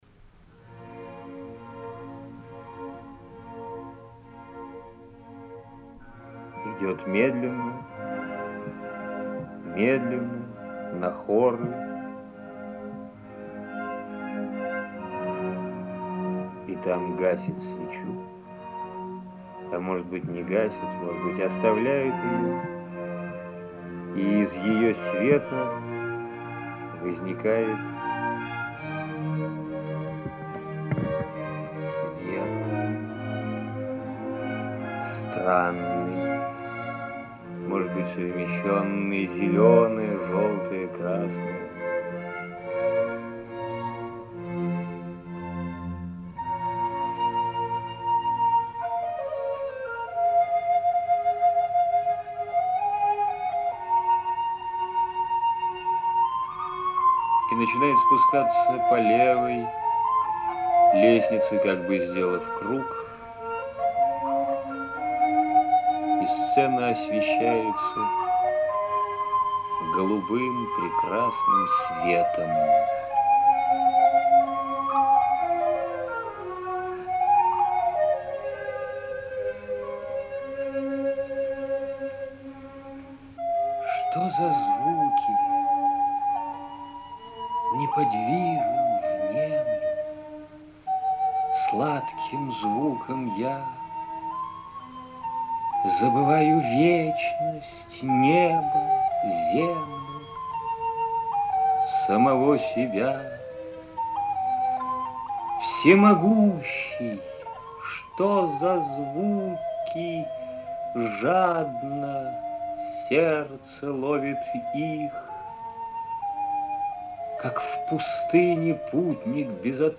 Lermontov-Zvuki-chitaet-Oleg-Dal-stih-club-ru.mp3